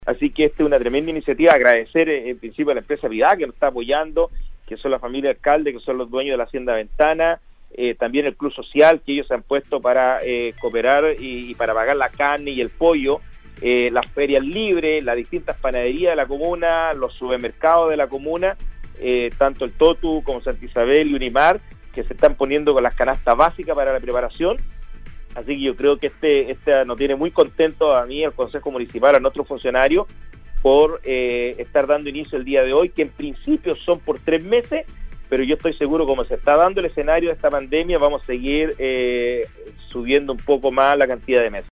La tarde de este miércoles, el alcalde de Vallenar, Cristian Tapia sostuvo un contacto telefónico en la emisión del noticiero de Nostálgica donde se refirió a las raciones de almuerzos que se están entregando a las familias más vulnerables de la comuna.